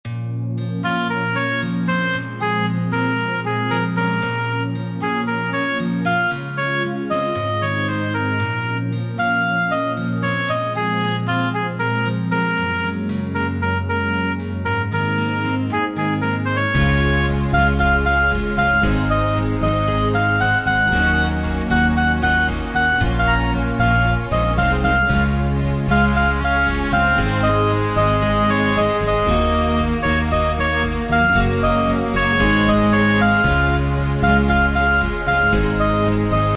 Tempo: 115 BPM.
MP3 with melody DEMO 30s (0.5 MB)zdarma